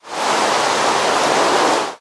rain1.mp3